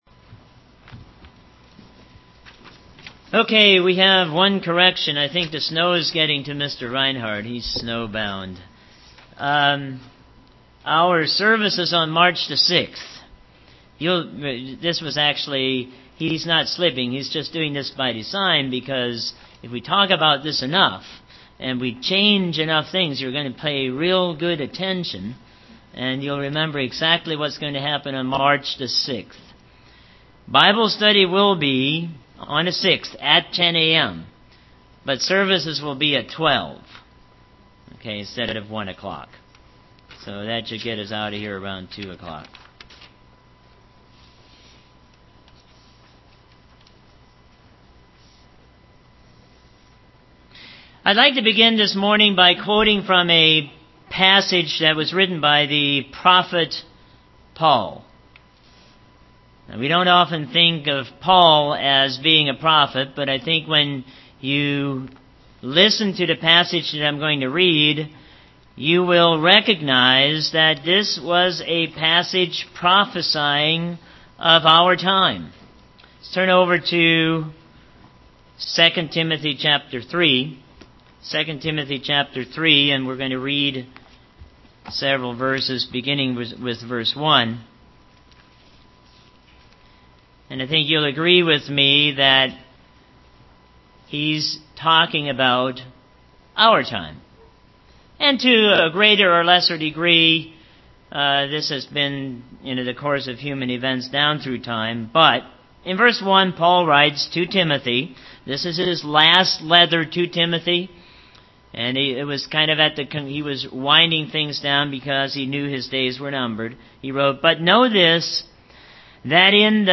The Right Turn UCG Sermon Studying the bible?